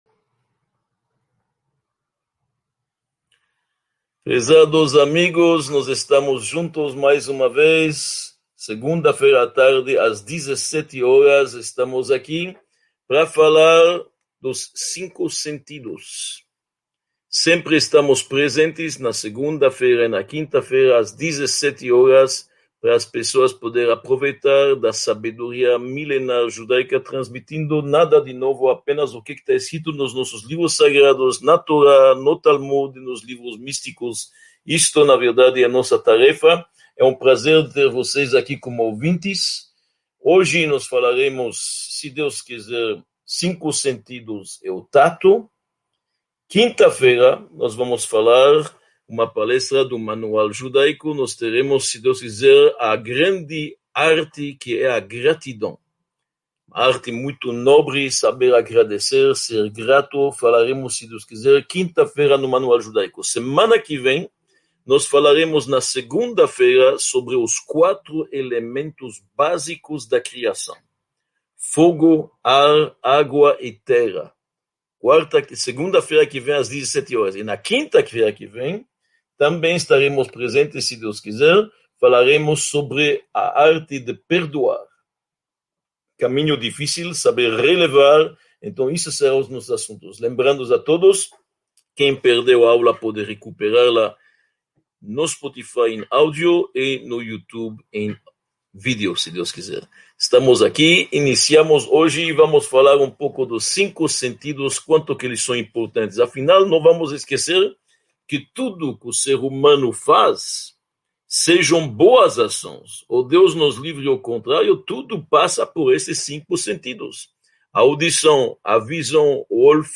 32 – Tato: sua força e efeitos | Módulo II – Aula 32 | Manual Judaico